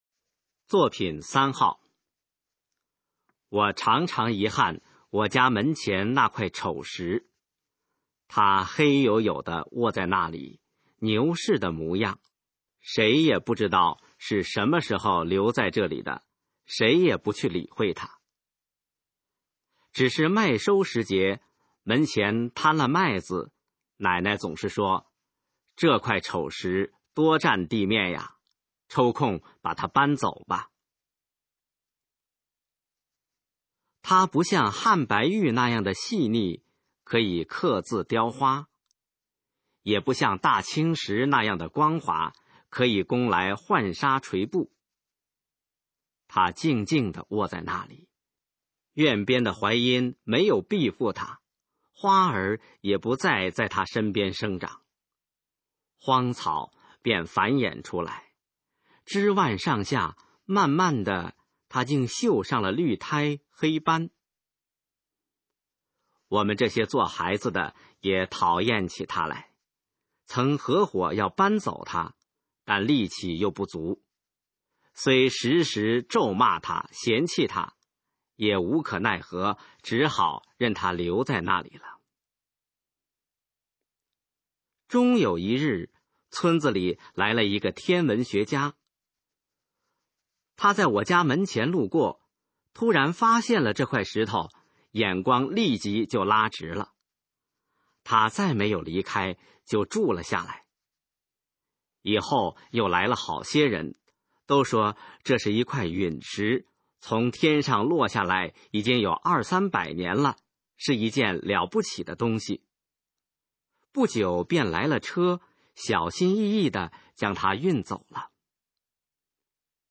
首页 视听 学说普通话 作品朗读（新大纲）
《丑石》示范朗读_水平测试（等级考试）用60篇朗读作品范读